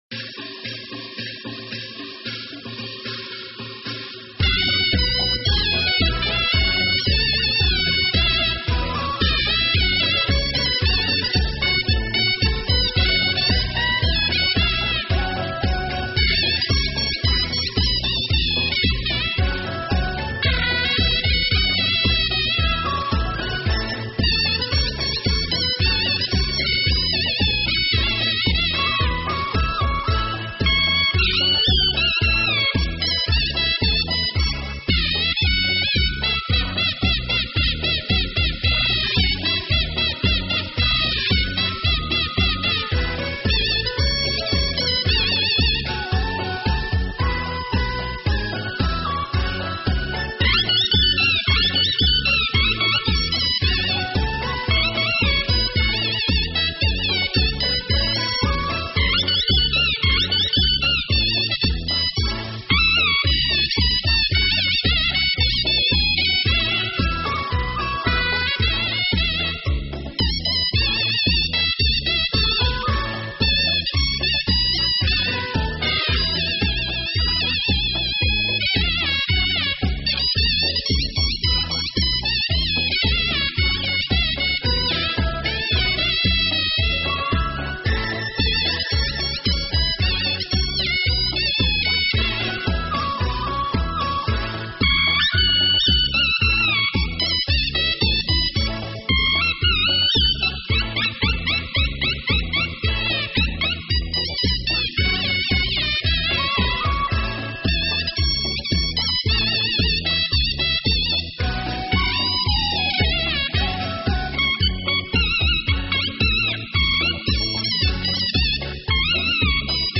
277–唢呐独奏 – 婚礼曲_东方“织”乐